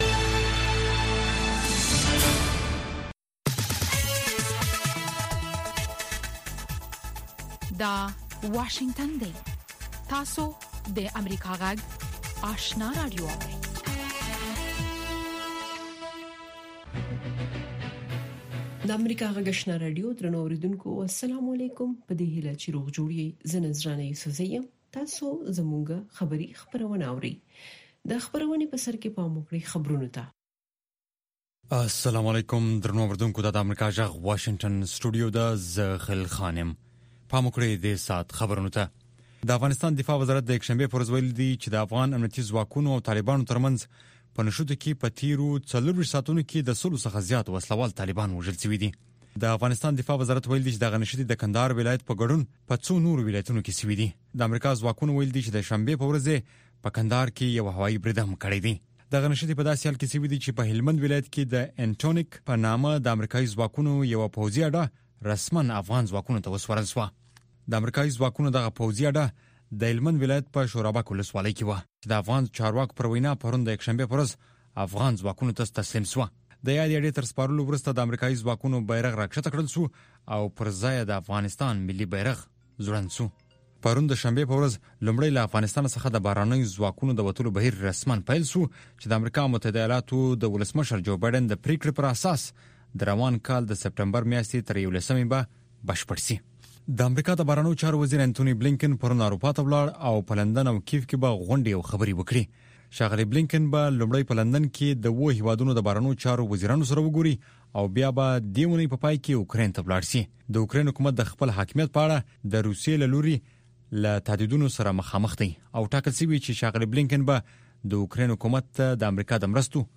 دویمه سهارنۍ خبري خپرونه